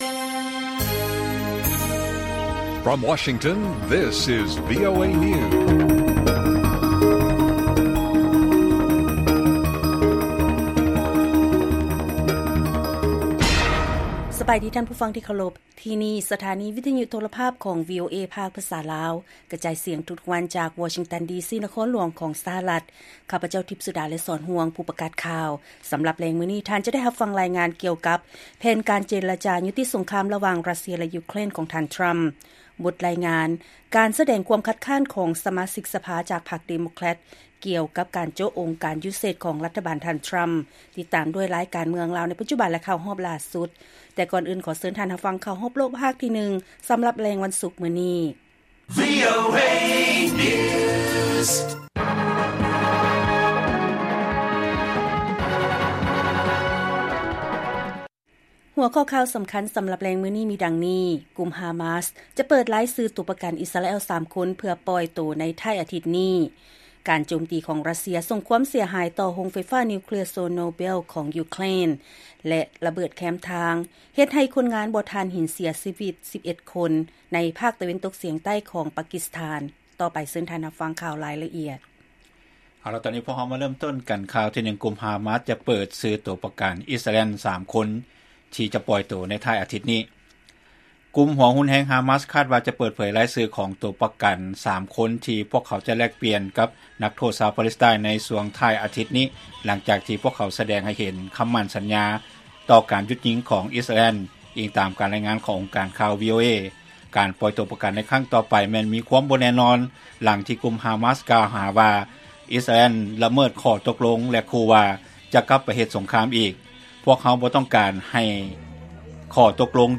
ລາຍການກະຈາຍສຽງຂອງວີໂອເອ ລາວ: ກຸ່ມຮາມາສຈະເປີດຊື່ໂຕປະກັນອິສຣາແອລ 3 ຄົນ ເພື່ອປ່ອຍໂຕໃນທ້າຍອາທິດນີ້.